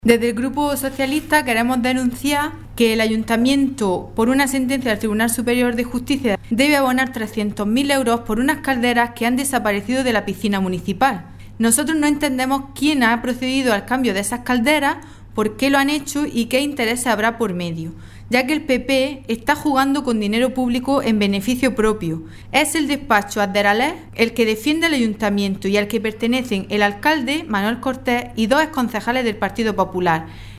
Teresa Piqueras, portavoz socialista en el Ayuntamiento de Adra